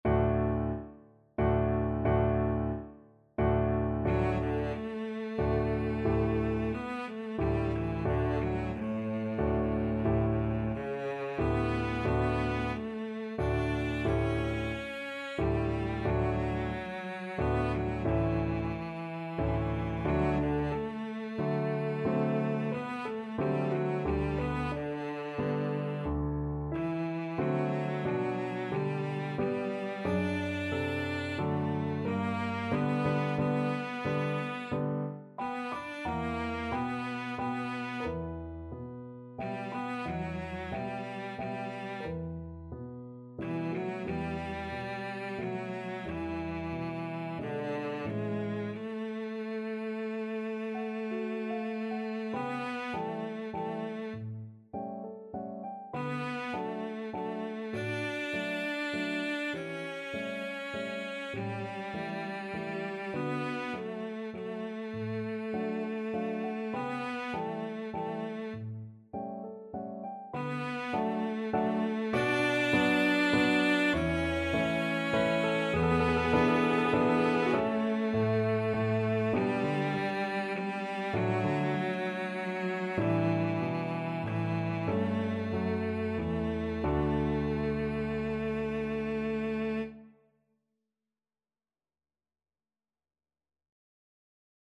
Cello
3/4 (View more 3/4 Music)
D major (Sounding Pitch) (View more D major Music for Cello )
~ = 90 Allegretto moderato
Classical (View more Classical Cello Music)